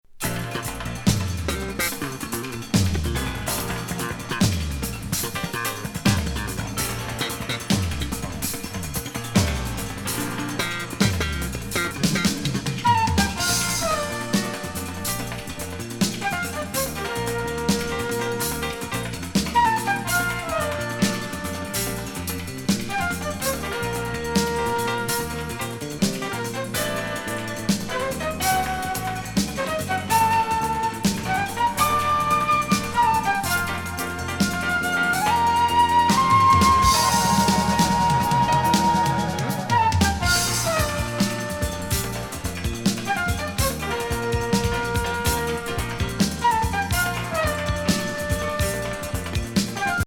ブラジリアン・ドラマーの’89年傑作！
ソリッド・ドラミングなブラジリアン・